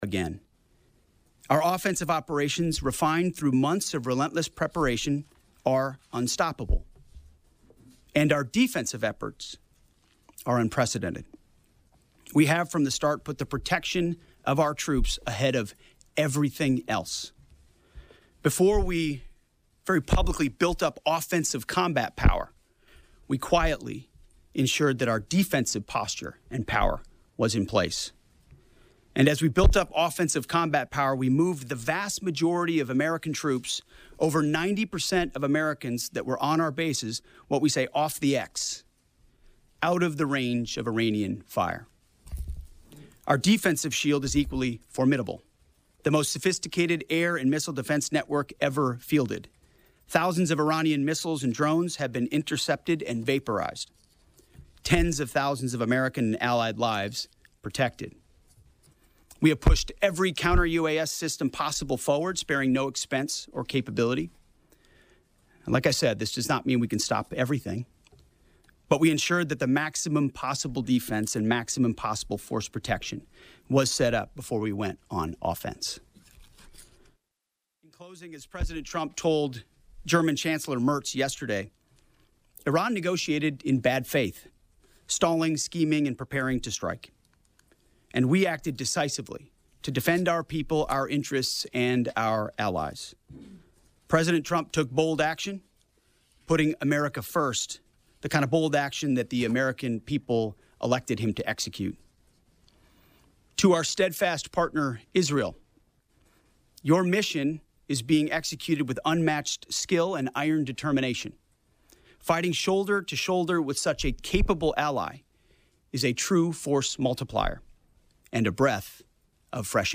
Pentagon Live Press Conference, Pensacola Mayor DC Reeves